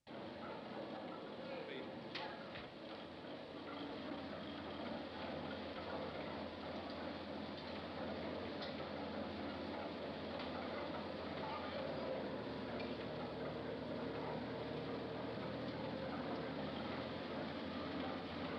do not disturb - texture.wav